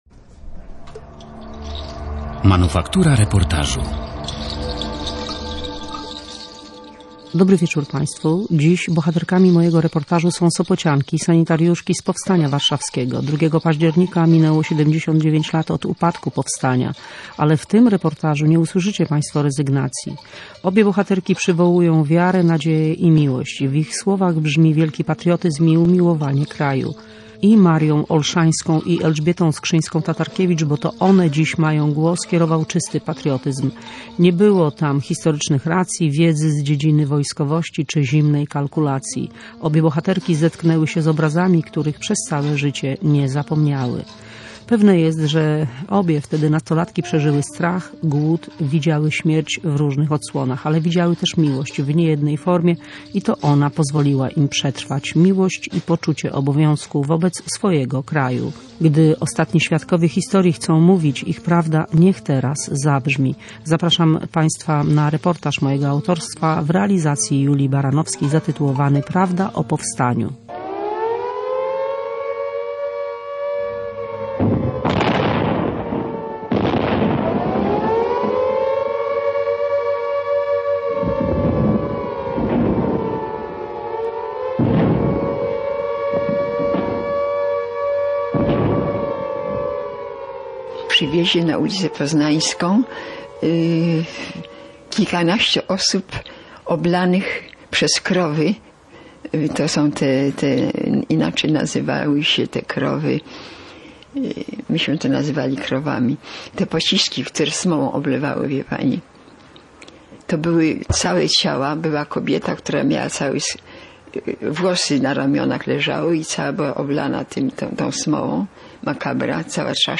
Dawne dziewczyny z powstania wspominają swoją walkę. Zapraszamy do wysłuchania reportażu